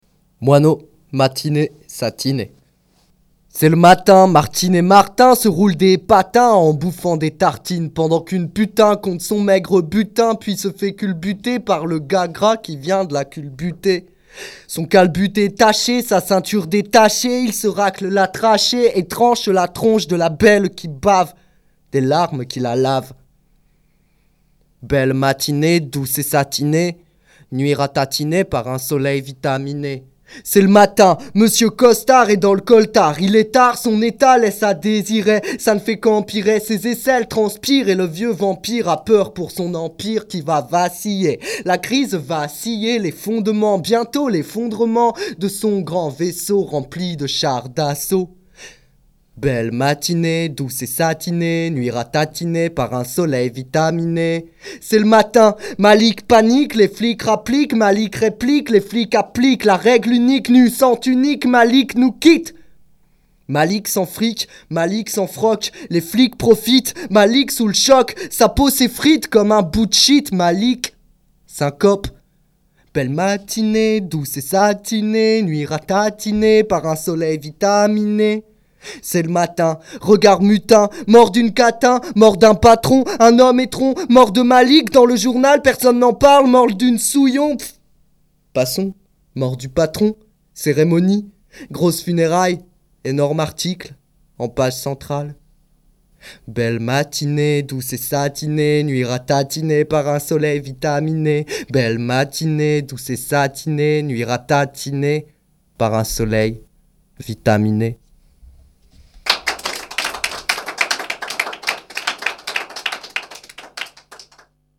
ateliers slam , écriture et enregistrement de séquences
séquence slam 11